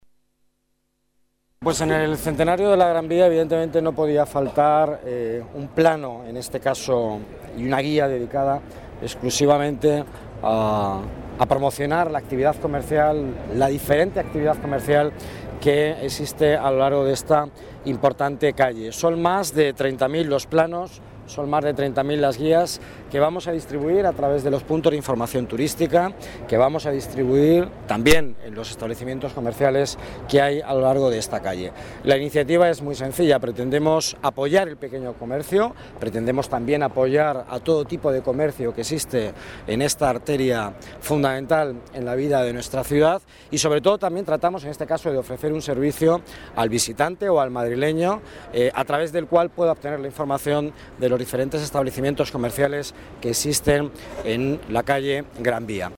Nueva ventana:Declaraciones delegado Economía, Miguel Ángel Villanueva: nueva guía y plano Gran Vía